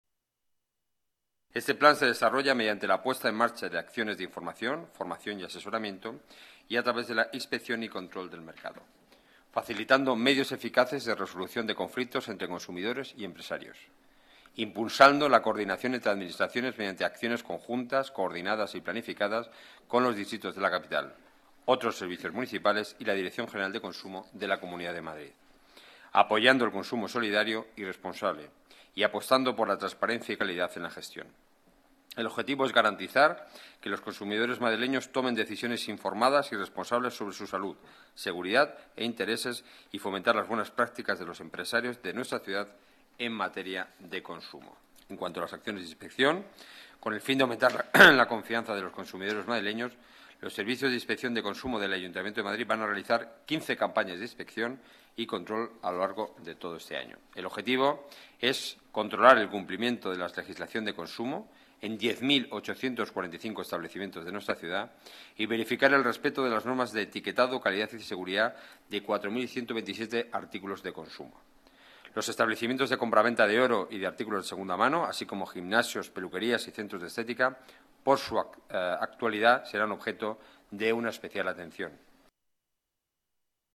Nueva ventana:Declaraciones del portavoz del Gobierno, Enrique Núñez: Hoja ruta servicios consumo